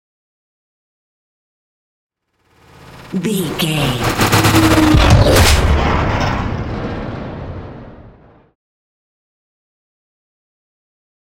Sci fi whoosh to hit big
Sound Effects
Atonal
dark
futuristic
tension
woosh to hit